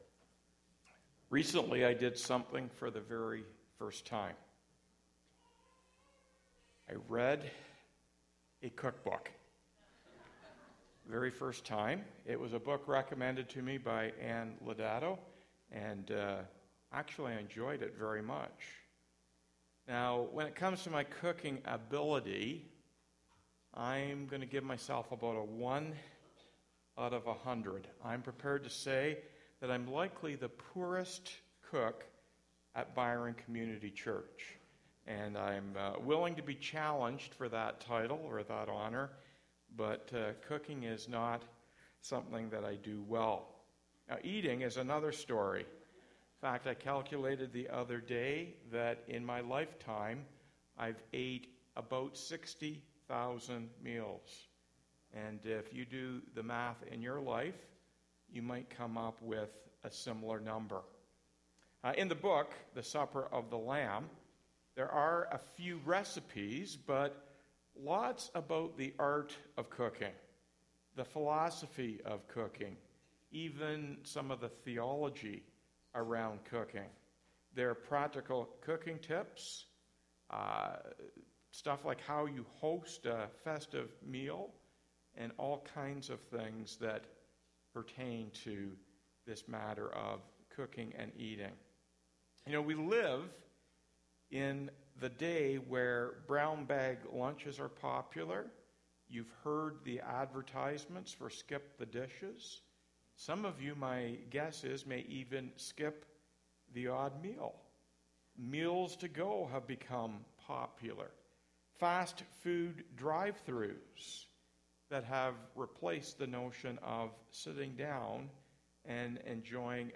Weekly Sermons